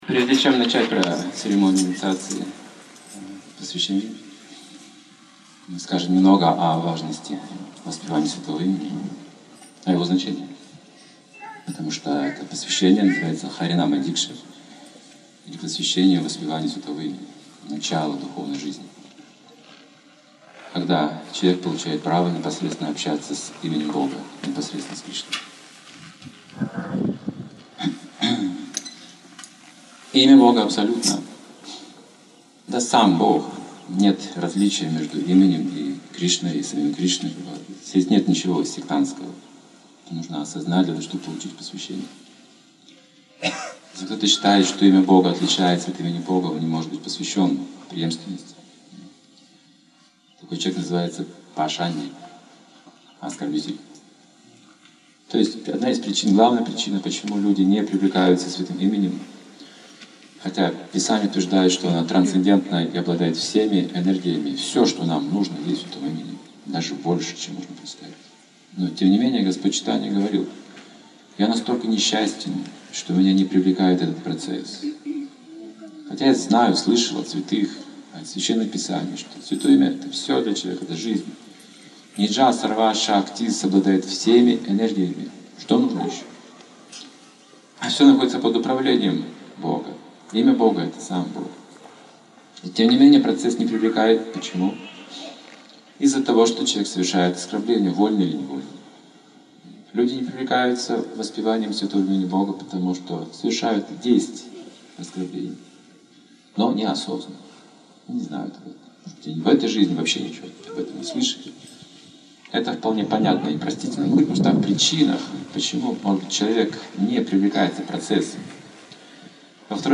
2008, Омск, Лекция перед инициацией, Наука внимательного воспевания